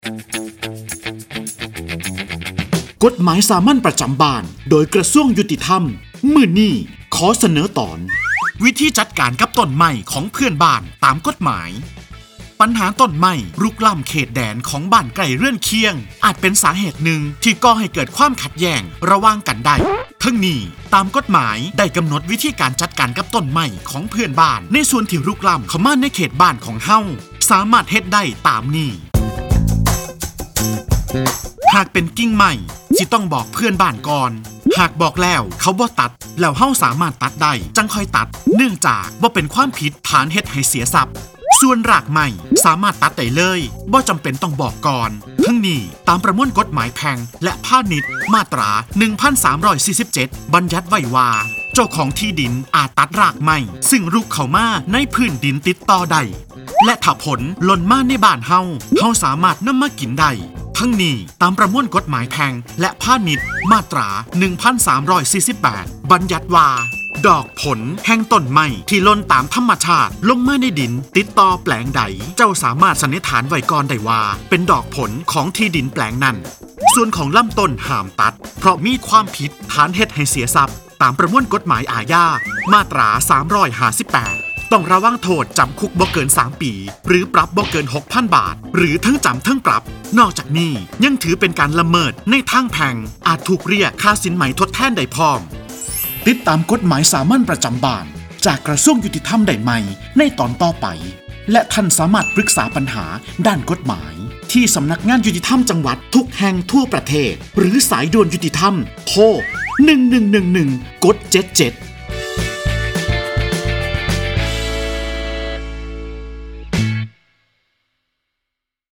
กฎหมายสามัญประจำบ้าน ฉบับภาษาท้องถิ่น ภาคอีสาน ตอนวิธีจัดการกับต้นไม้ของเพื่อนบ้านตาม
ลักษณะของสื่อ :   คลิปเสียง, บรรยาย